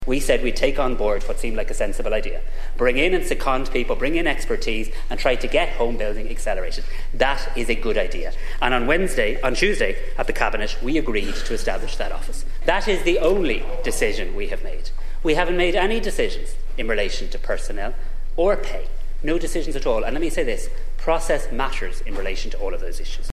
Dail debates proposed appointment of ‘housing tzar’
While sources say that amount could be reduced before it’s agreed – Tánaiste Simon Harris told Donegal Deputy Pearse Doherty this afternoon that nothing is final……….